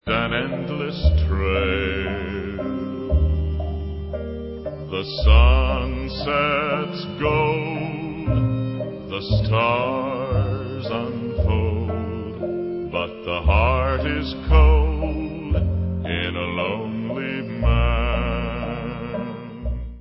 Western soundtracks